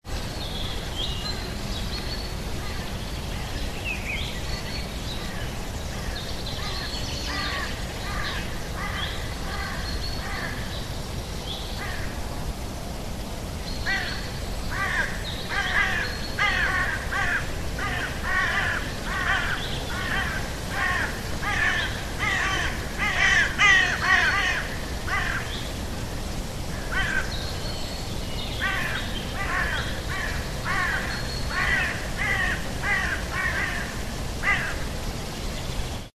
Ambience.ogg